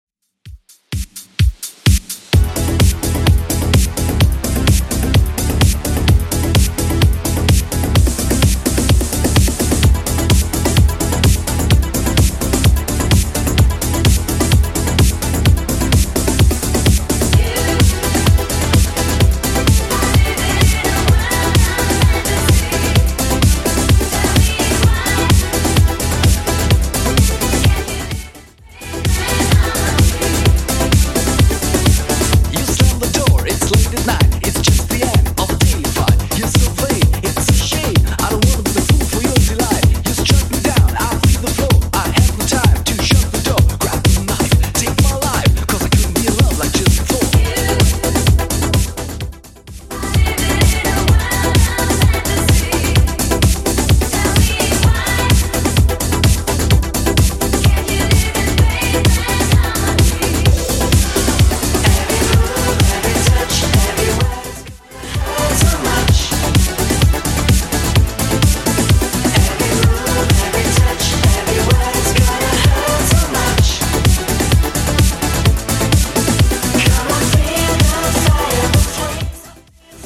Genre: 80's
BPM: 115